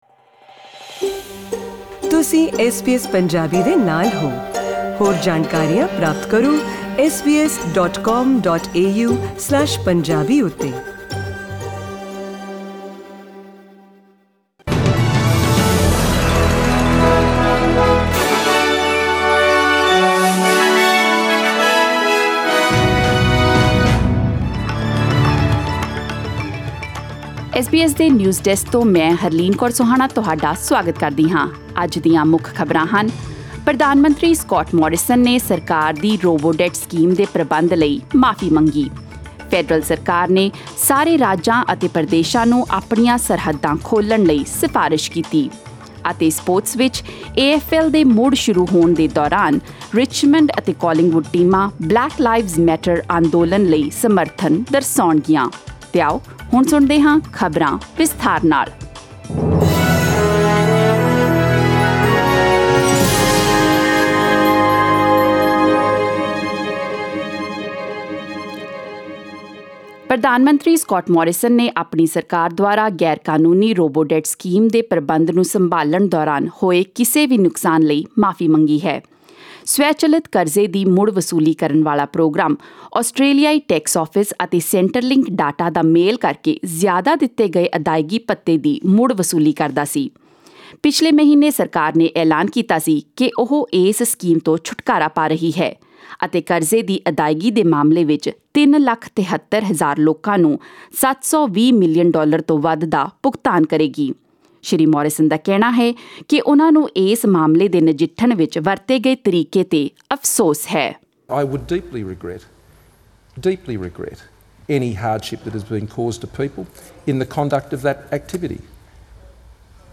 In this bulletin ** Prime Minister Scott Morrison apologises for the government's handling of the robodebt scheme...